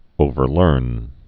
(ōvər-lûrn)